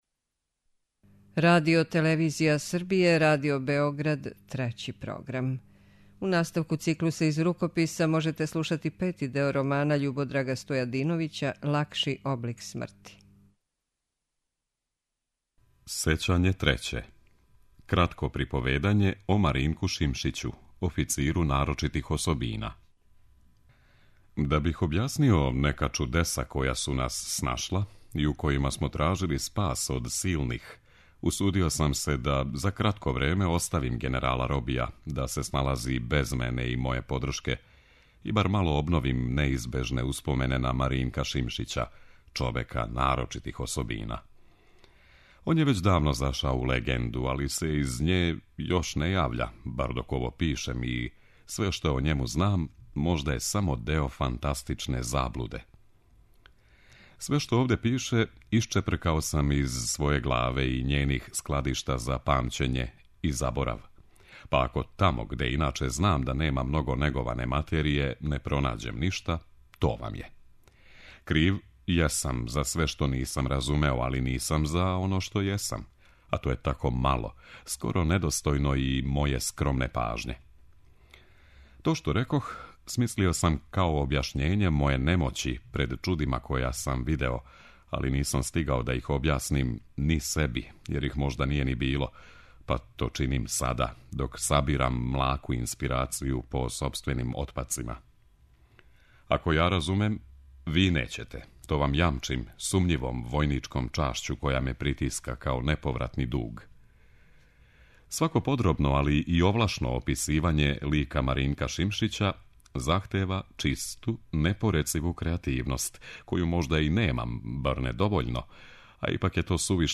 преузми : 38.45 MB Књига за слушање Autor: Трећи програм Циклус „Књига за слушање” на програму је сваког дана, од 23.45 сати.